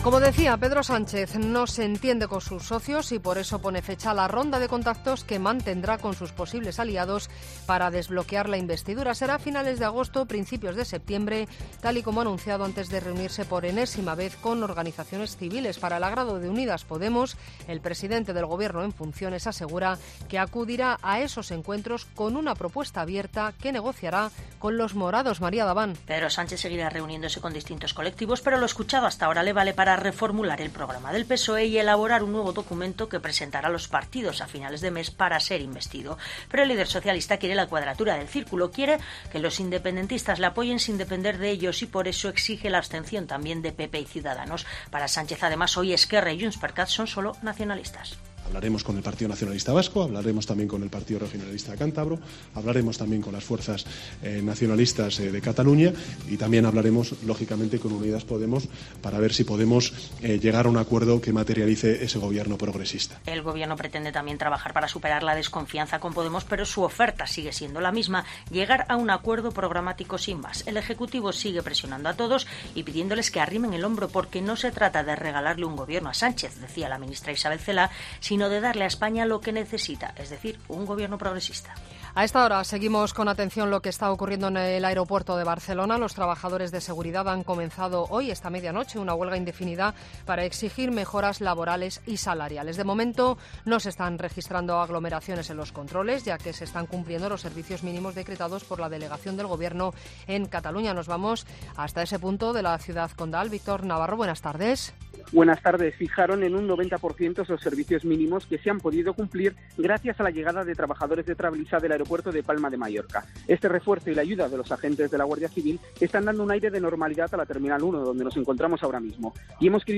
Boletín de noticias de COPE del 9 de agosto de 2019 a las 19.00 horas